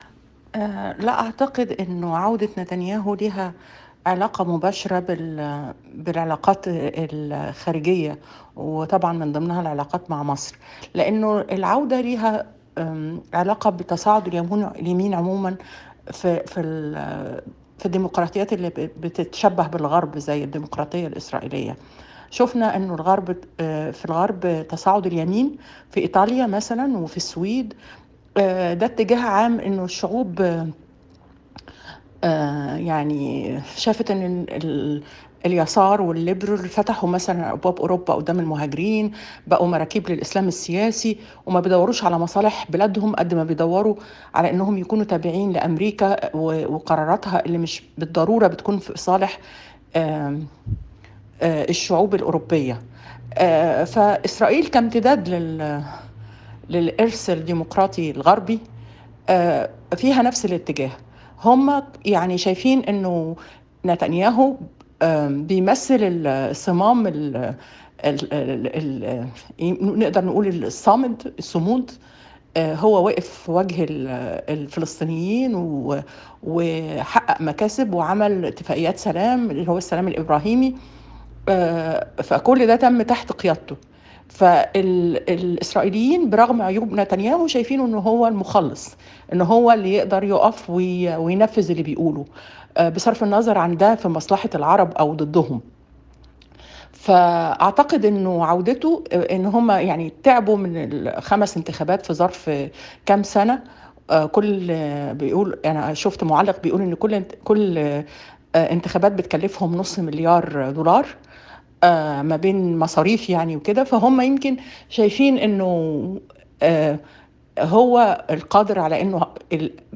سهام مصطفى، عضو مجلس النواب المصري